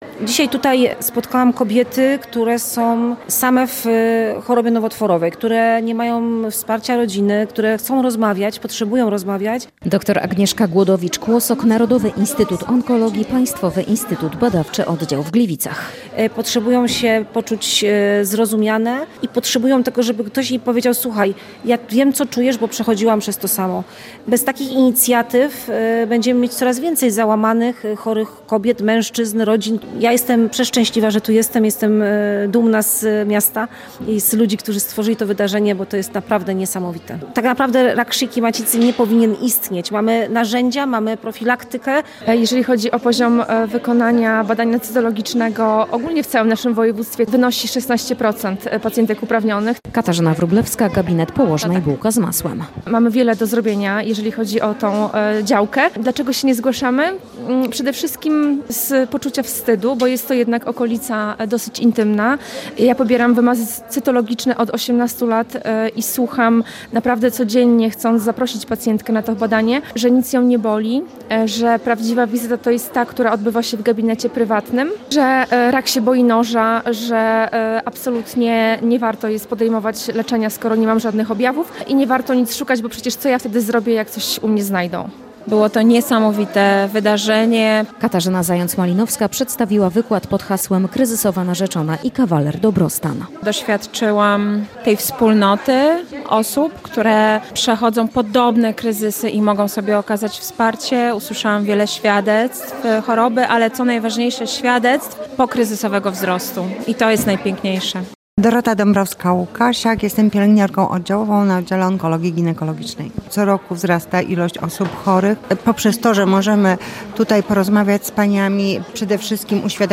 Spotkanie, które odbyło się w Hali Kultury było skierowane do kobiet w trakcie i po chorobie nowotworowej, ich bliskich oraz każdej pani, która chce świadomie zadbać o swoje zdrowie.